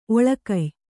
♪ oḷakay